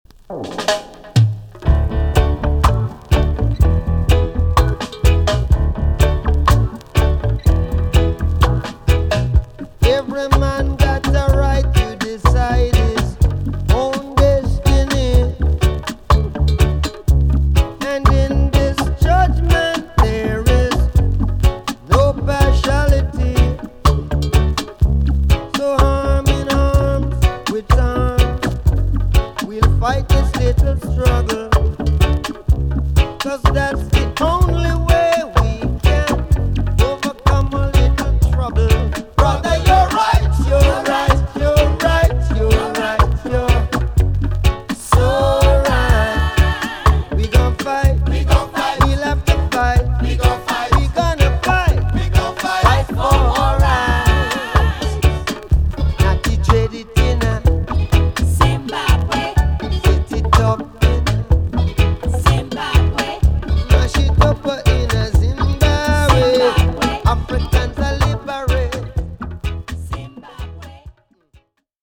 B.SIDE EX-~VG+ 少しチリノイズの箇所がありますが音は良好です。